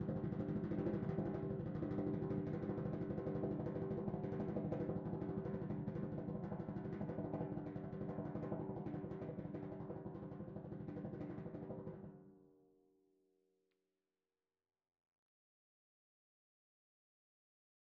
Timpani5_Roll_v3_rr1_Sum.wav